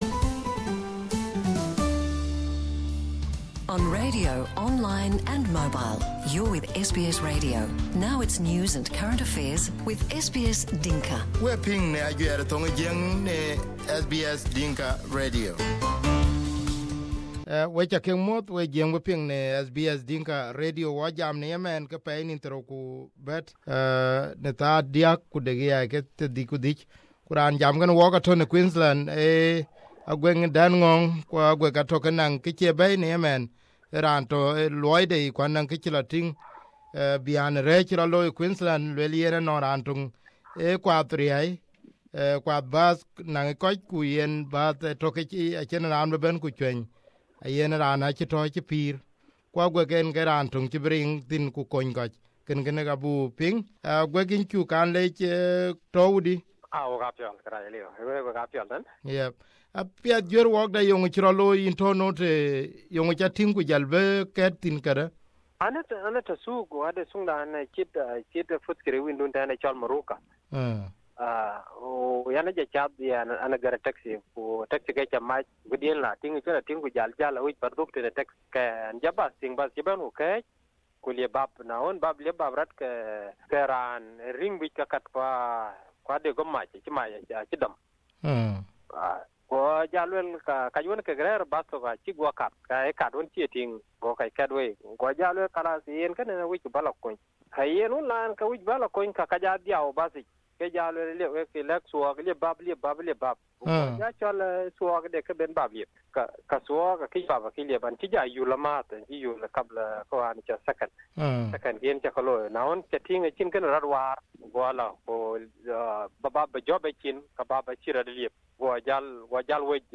SBS Dinka